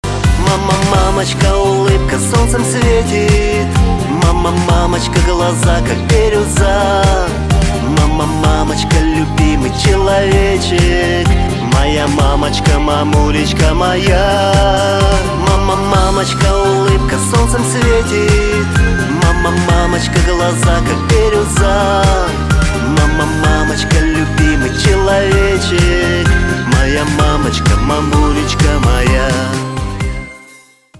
• Качество: 256, Stereo